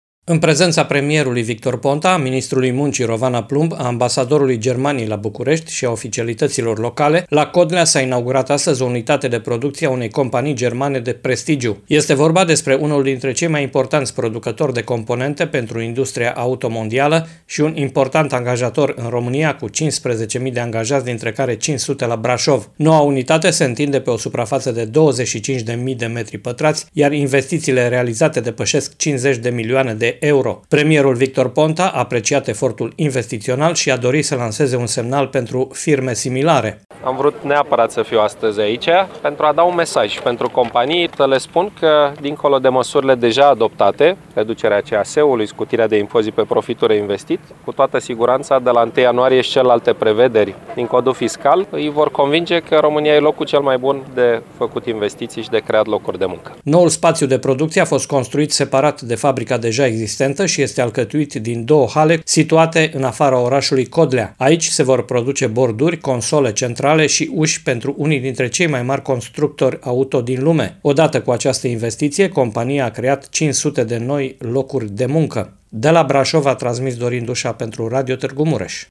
În prezenţa premierului Victor Ponta, a ministrului Muncii, Rovana Plumb, a ambasadorului Germaniei la Bucureşti şi a oficialităţilor locale, la Codlea s-a inaugurat, astăzi, o nouă unitate de producţie a unei companii germane de prestigiu.
Victor PontaPremierul Victor Ponta a apreciat efortul investiţional şi a dorit să lanseze un semnal pentru firme similare: